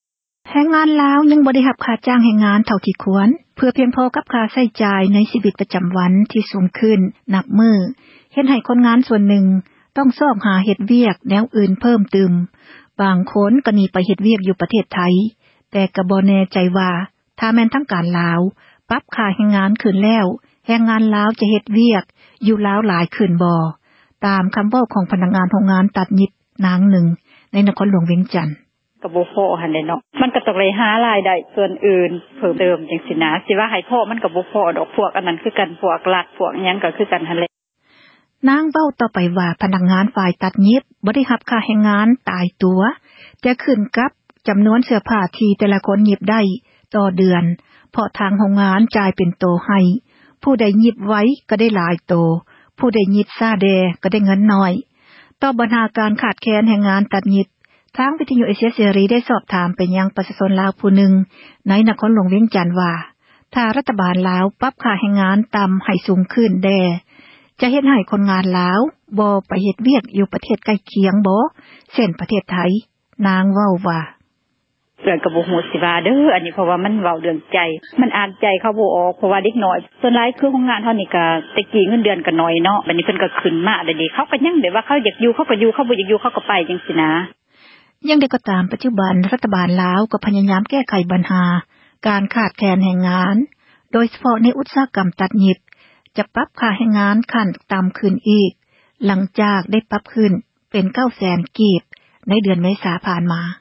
ຕາມ ຄໍາເວົ້າ ຂອງ ພະນັກງານ ໂຮງງານ ຕັດຍິບ ຄົນນຶ່ງ ໃນ ນະຄອນຫຼວງ ວຽງຈັນ: